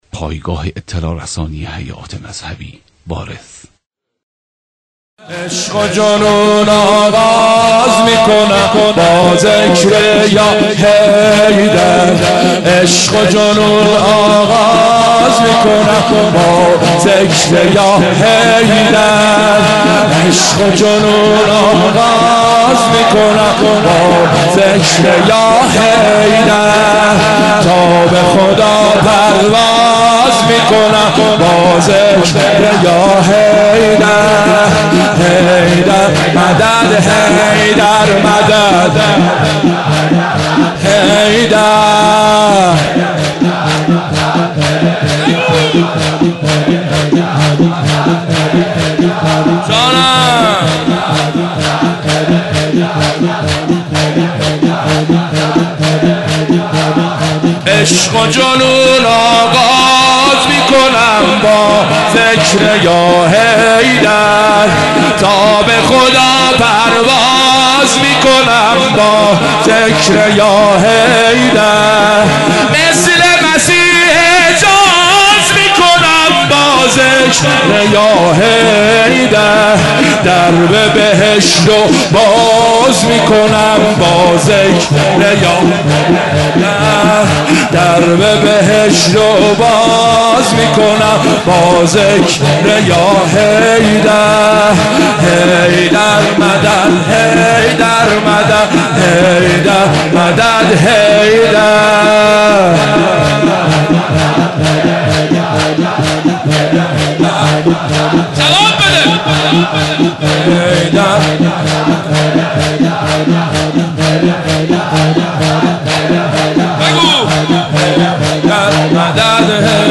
مداحی حاج حسین سیب سرخی به مناسبت شهادت امیرالمومنین (ع)